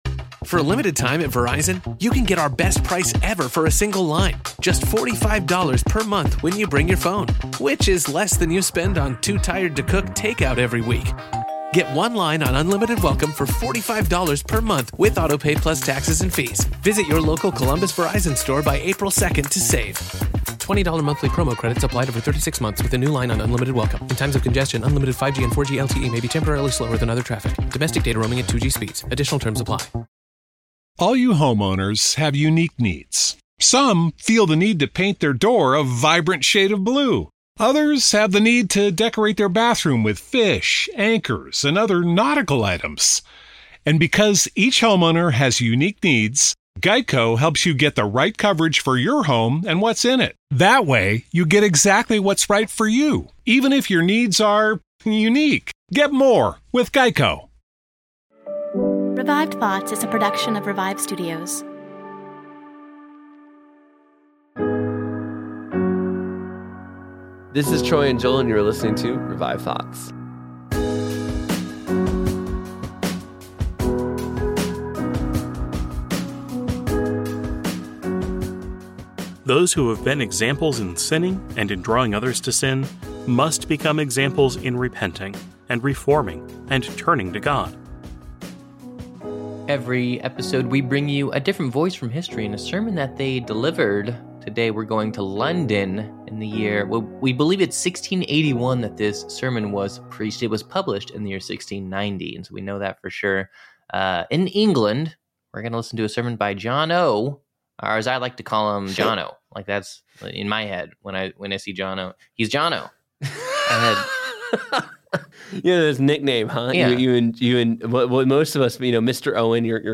Listen to this sermon that he delivered to the English protestants towards the end of his life.